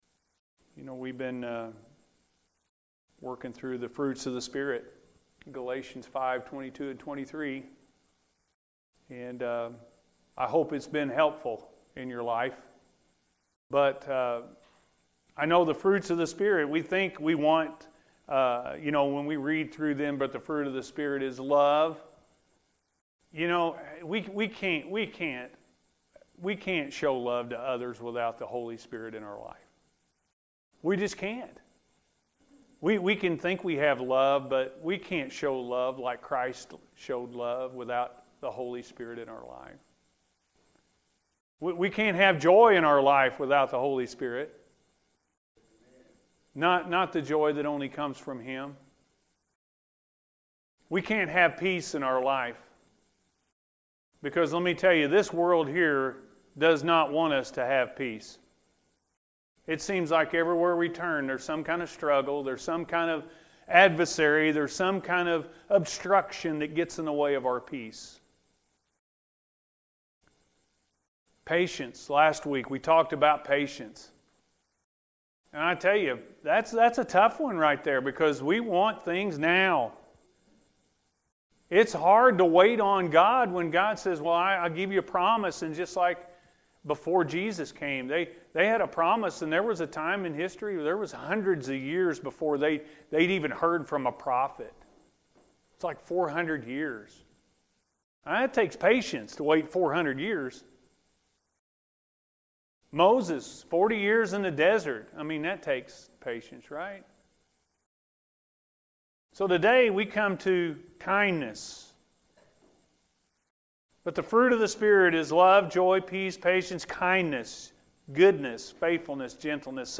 Kindness-A.M. Service – Anna First Church of the Nazarene